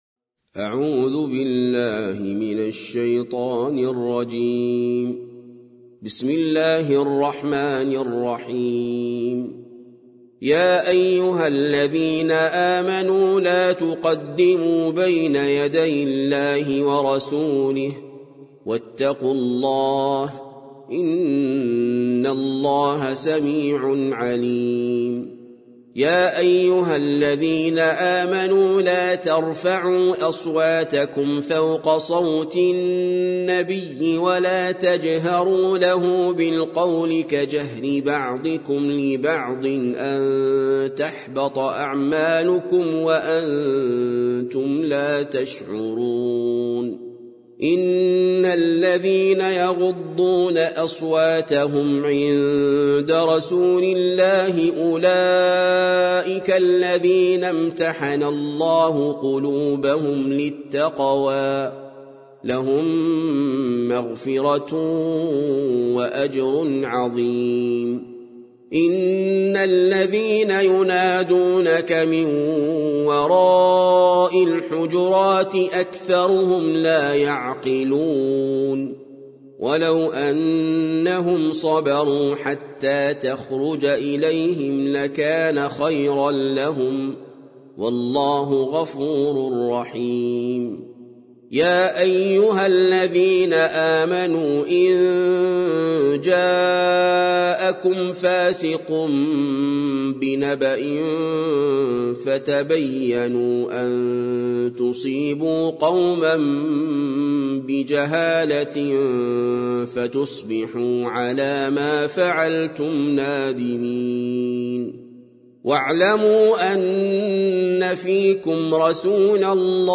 سورة الحجرات - المصحف المرتل (برواية حفص عن عاصم)
جودة عالية